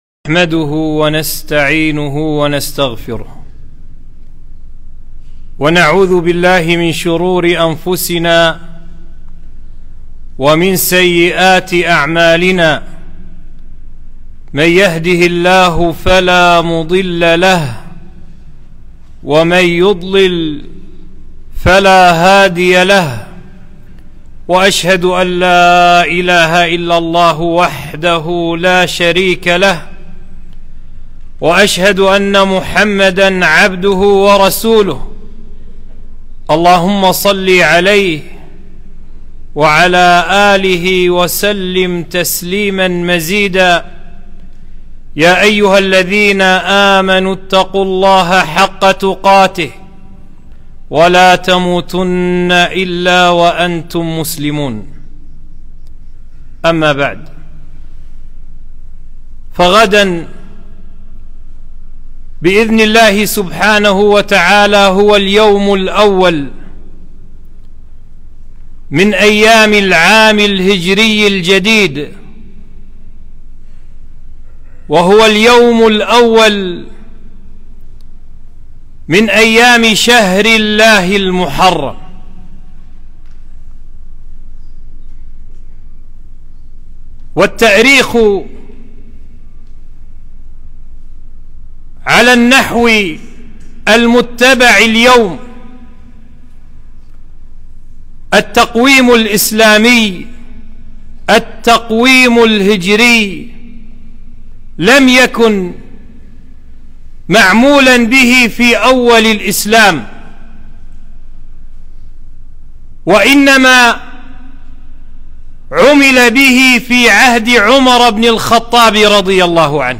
خطبة - شهر الله المحرم 1444